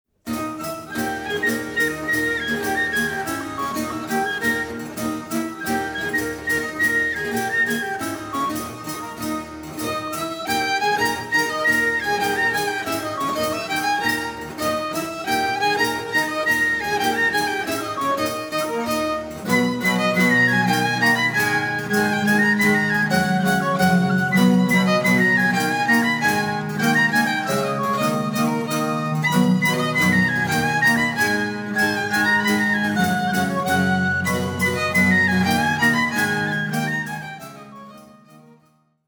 Recorded on November 25 & 26, 2000 in Santa Cruz, California
Genre: Early Music, Ottoman Classical.